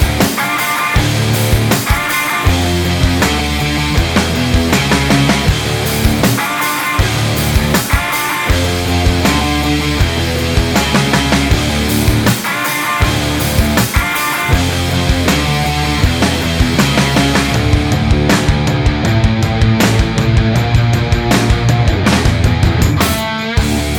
Minus Main Guitars Rock 4:06 Buy £1.50